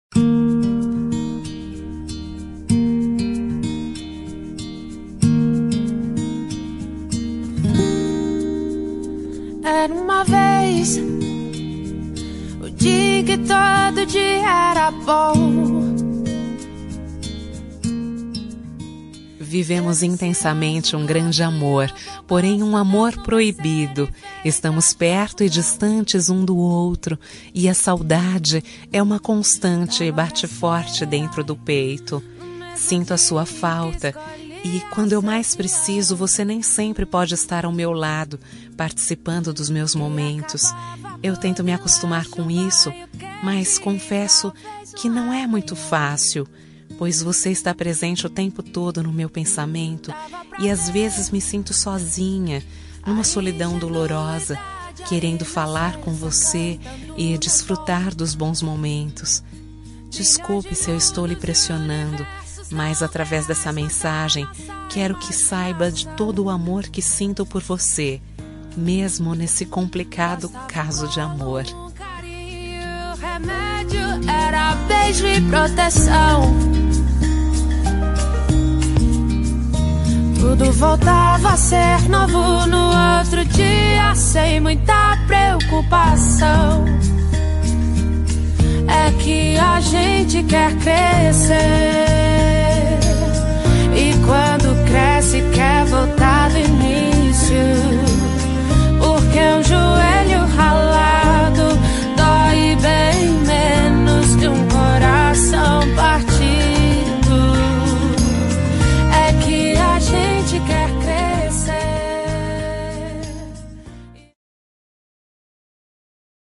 Telemensagem Para Amante – Voz Feminina – Cód: 6758